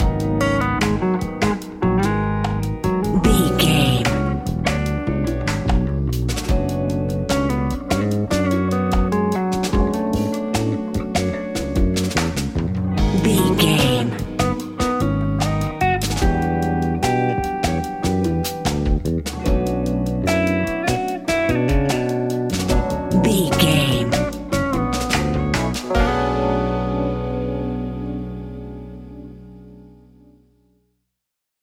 Ionian/Major
B♭
hip hop